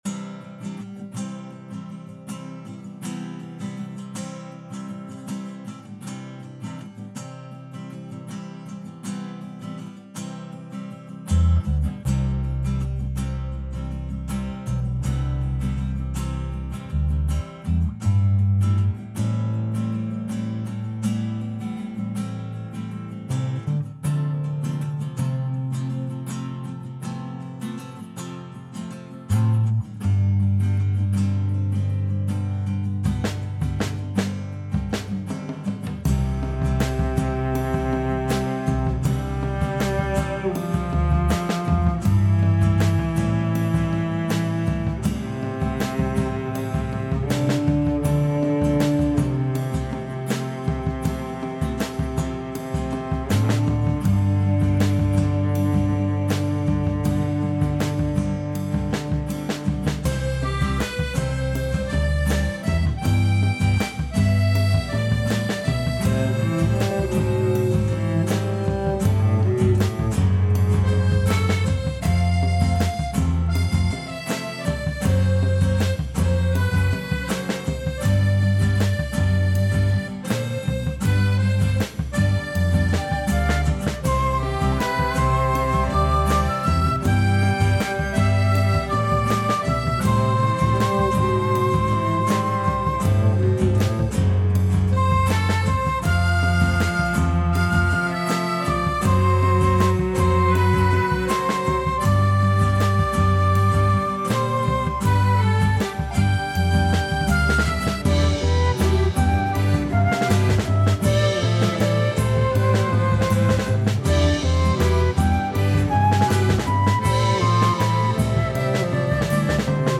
video game remix